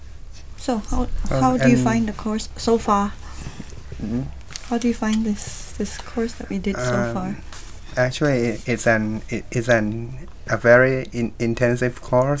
FBrun = female from Brunei MThai = male, from Thailand
Note the pause of 1.3 seconds — the others were waiting for him to respond, so he was unable to let it go, and he was forced to ask for clarification.